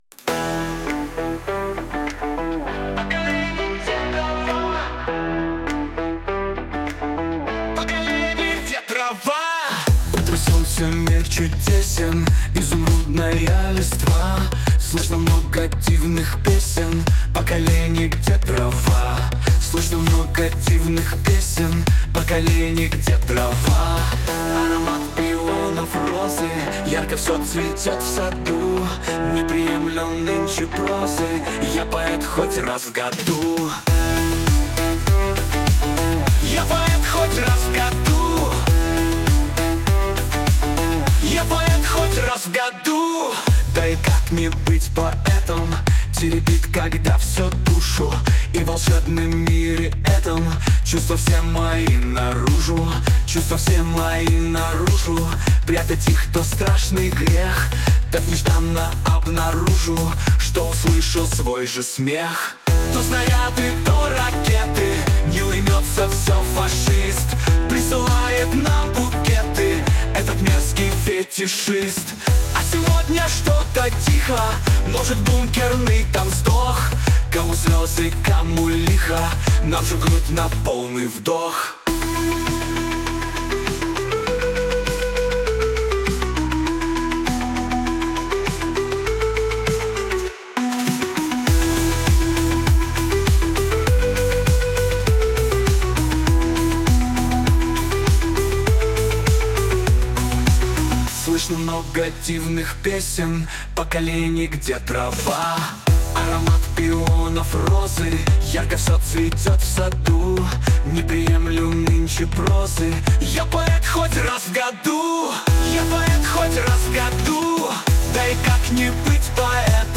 Гарне та зворушливе звучання пісні, котра полонить душі!